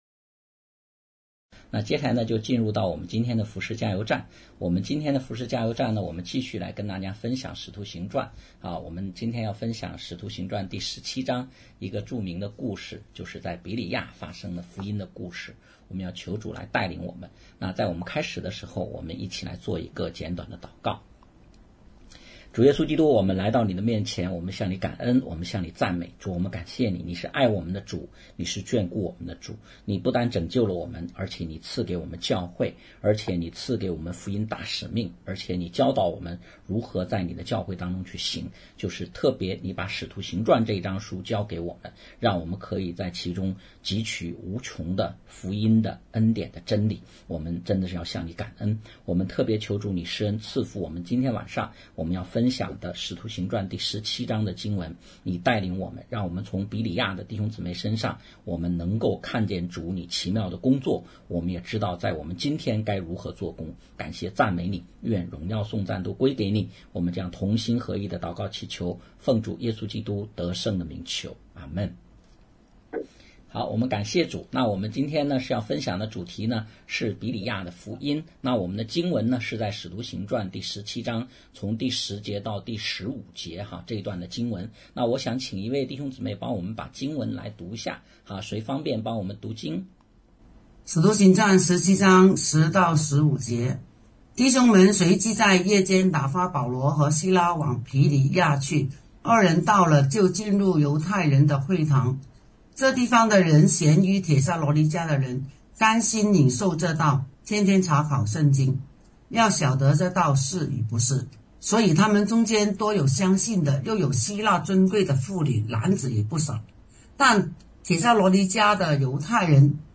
讲道录音 点击音频媒体前面的小三角“►”就可以播放 https